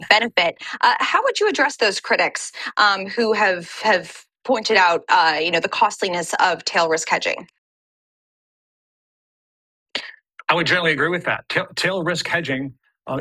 Example for interview audio enhanced by ai|coustics reconstructive voice enhancement.
Interview_Audio_aic_enhanced.wav